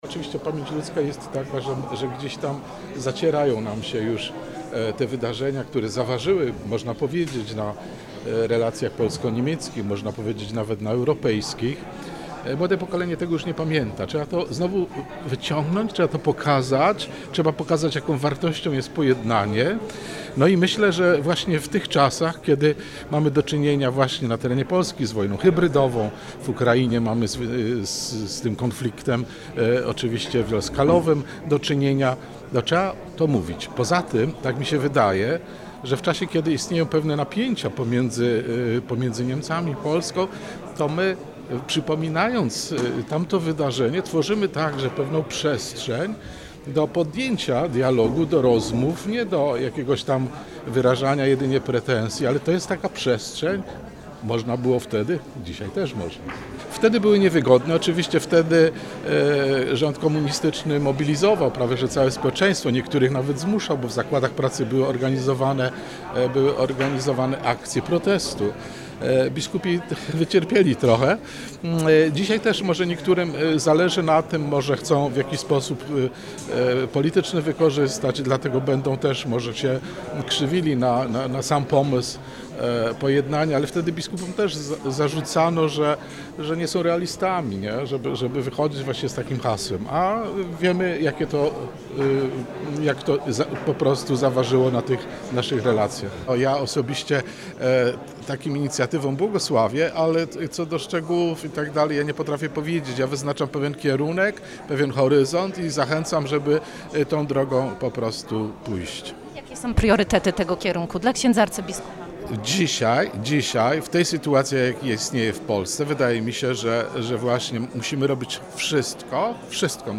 Z okazji 60. rocznicy orędzia biskupów polskich do niemieckich w gmachu Muzeum Archidiecezjalnego we Wrocławiu odbyła się konferencja „Odwaga wyciągniętej ręki”.